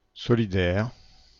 Ääntäminen
Ääntäminen (France): IPA: [sɔ.li.dɛʁ] (France) Bordeaux: IPA: [sɔ.li.dɛʁ] Haettu sana löytyi näillä lähdekielillä: ranska Käännöksiä ei löytynyt valitulle kohdekielelle.